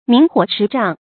明火持杖 míng huǒ chí zhàng
明火持杖发音
成语注音ㄇㄧㄥˊ ㄏㄨㄛˇ ㄔㄧˊ ㄓㄤˋ